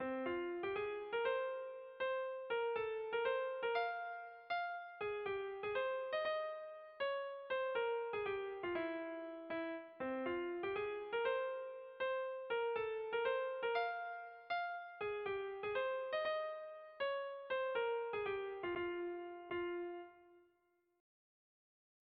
Bertso melodies - View details   To know more about this section
Gabonetakoa
Zortziko txikia (hg) / Lau puntuko txikia (ip)
A-B-A-B2